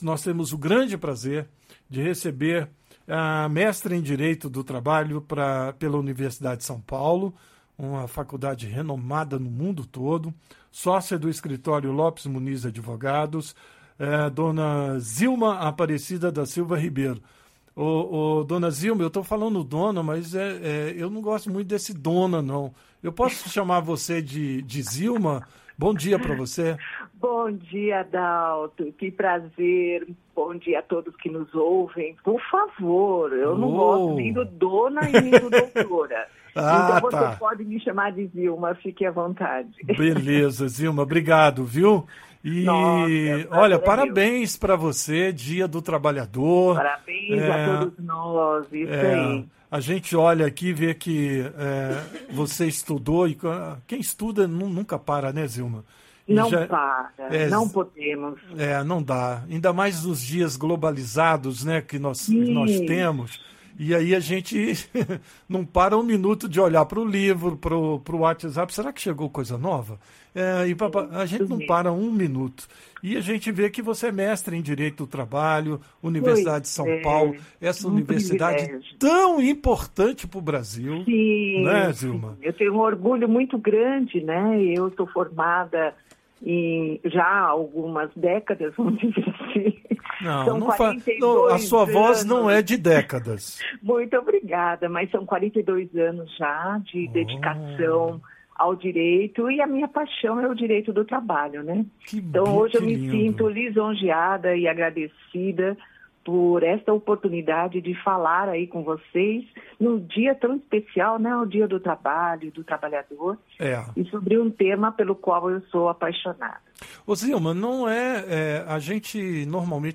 Mestre em Direito do Trabalho comenta mudanças na legislação ao longo dos anos e o desafio da inteligência artificial